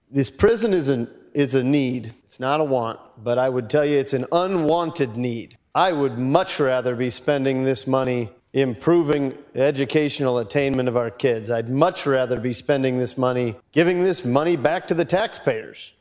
Republican Rep. Will Mortenson from Pierre also defended the spending.